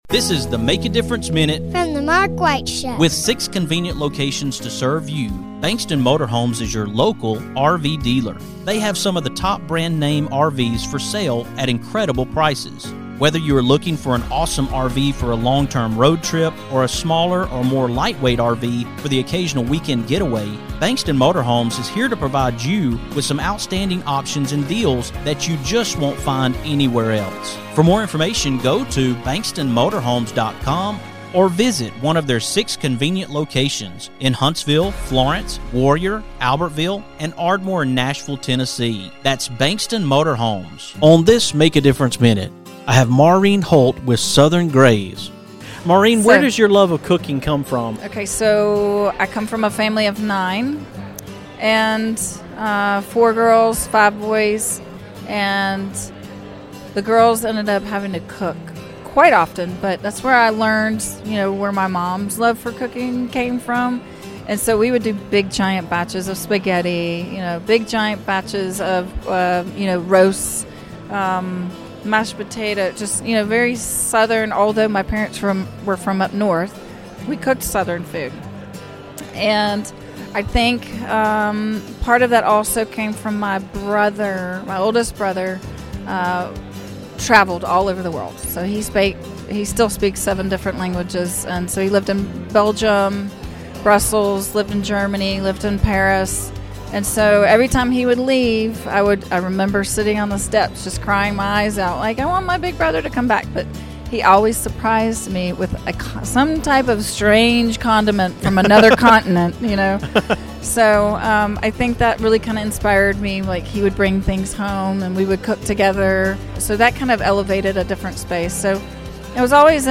On this MADM, I am coming to you from the Birmingham Home Show